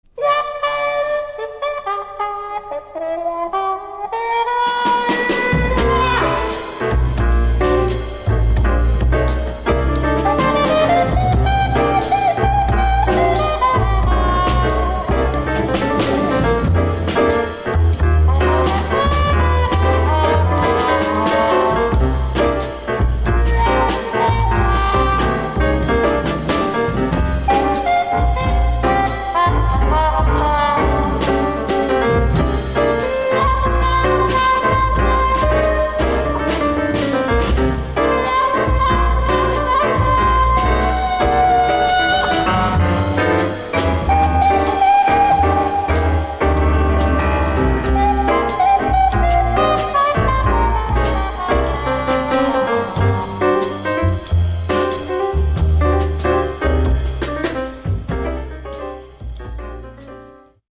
Soundtrack Jazz